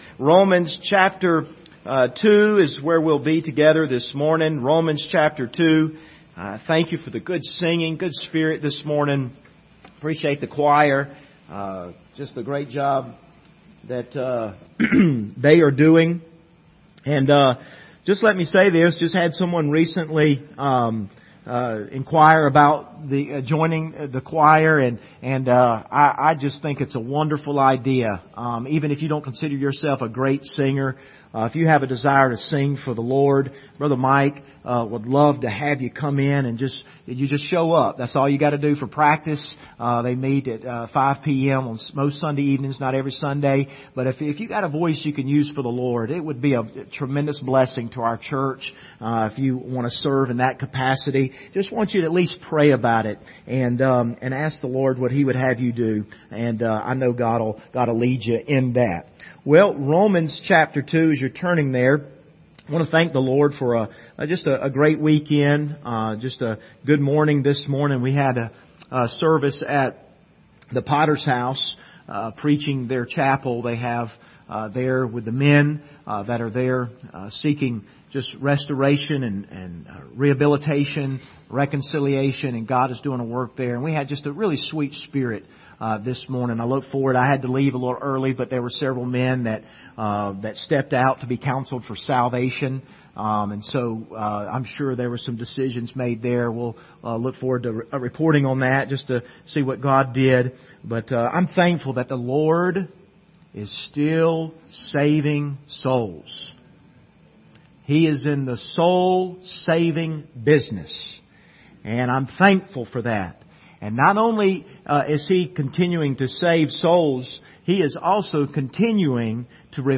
Passage: Romans 2:17-24 Service Type: Sunday Morning